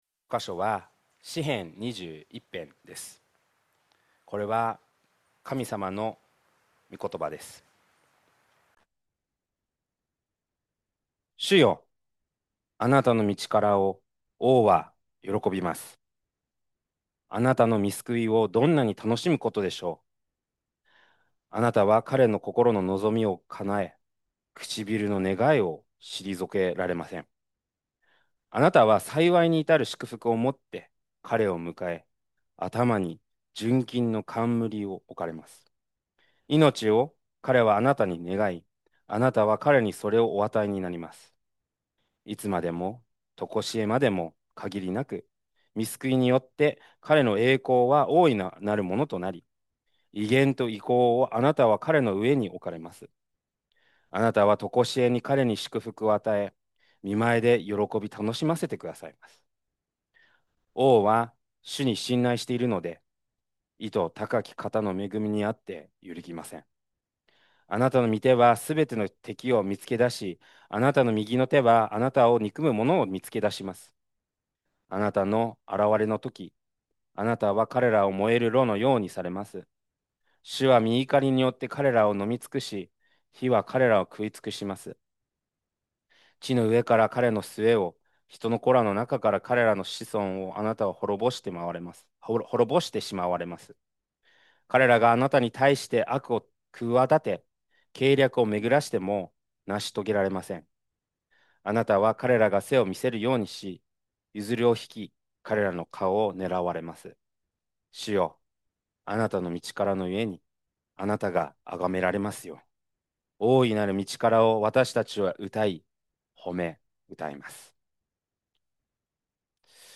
2026年3月29日礼拝 説教 「真の王の祈り」 – 海浜幕張めぐみ教会 – Kaihin Makuhari Grace Church